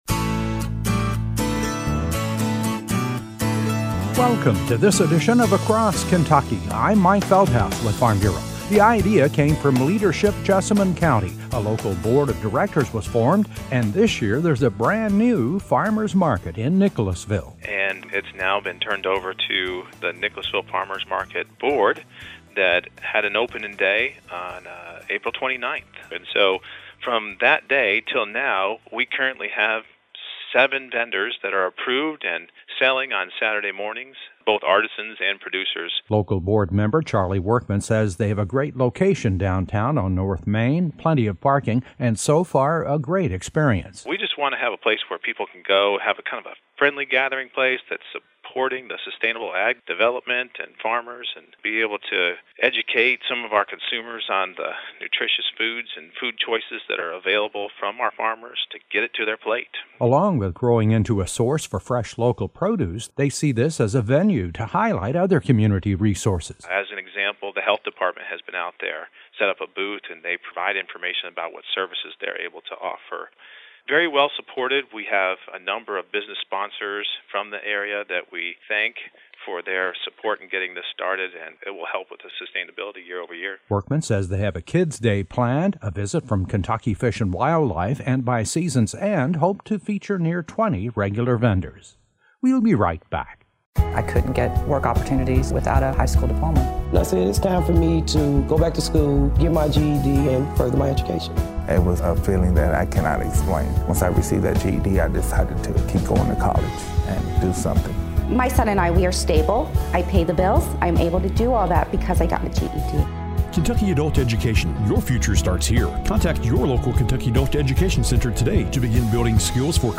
A feature report on a brand new farmers’ market that’s just opened this season in Nicholasville.